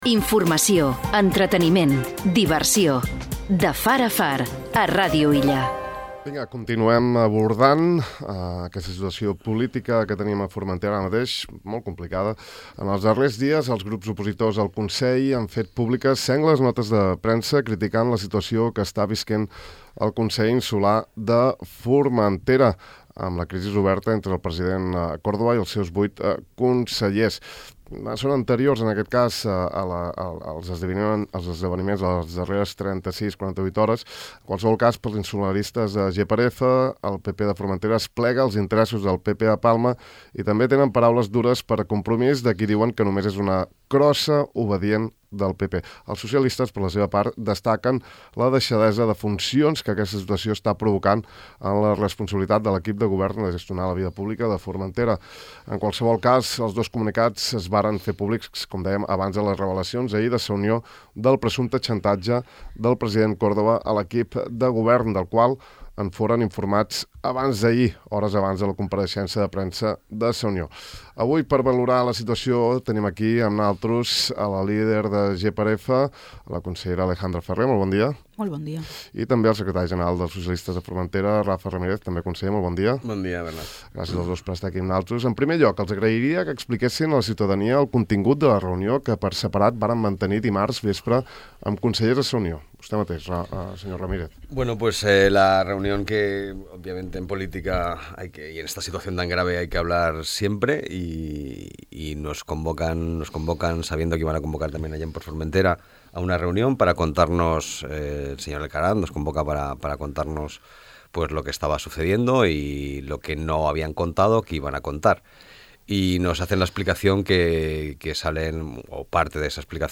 Avui, per valorar els esdeveniments que s’han succeït en les darreres hores hem convidat al De far a far, a la líder de GxF , Alejandra Ferrer , i al secretari general del PSOE, Rafael Ramírez.